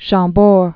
(shäɴ-bôr)